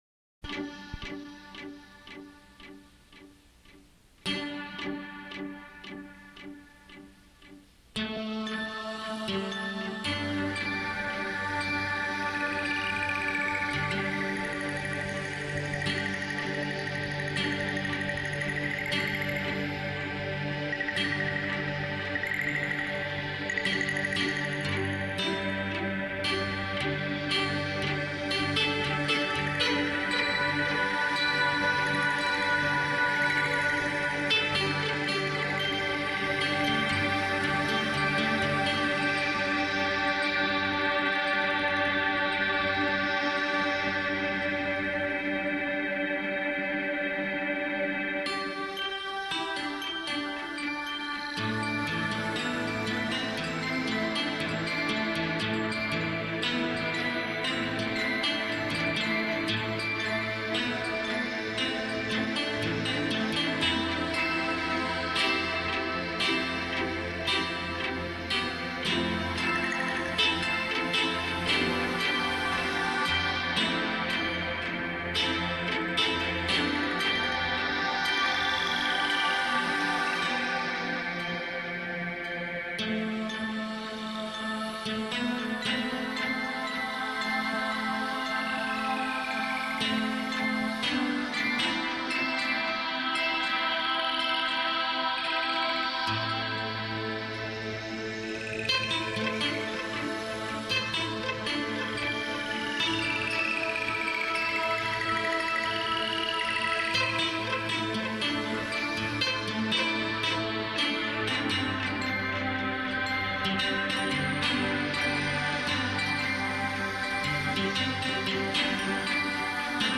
a meditation music CD...
all music recorded live in-studio